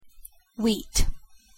pronunciation-en-wheat.mp3